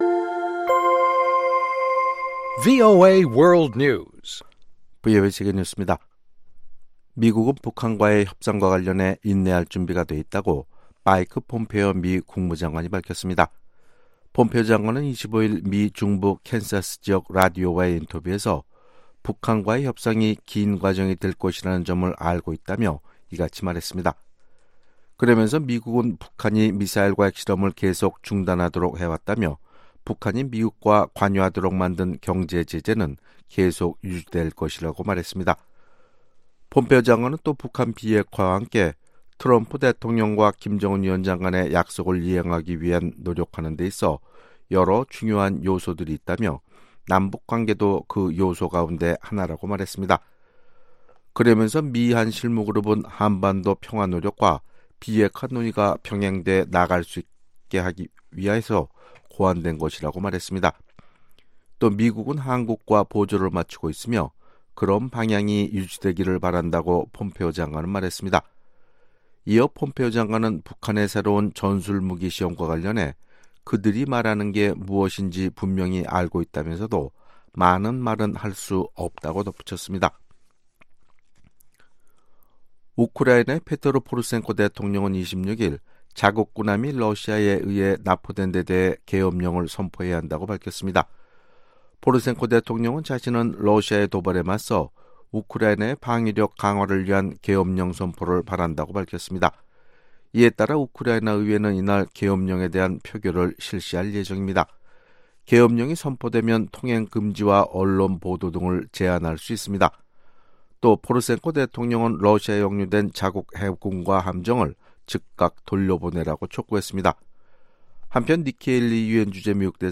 VOA 한국어 아침 뉴스 프로그램 '워싱턴 뉴스 광장' 2018년 11월 27일 방송입니다. 미 국무부가 북한의 핵 활동에 대한 국제원자력기구(IAEA)의 우려에 동감한다는 입장을 밝혔습니다. 미국 정부의 대북 제재와 북한 여행 금지 조치가 대북 인도적 지원 활동을 어렵게 한다는 구호단체들으ㅣ 주장이 이어지고 있습니다.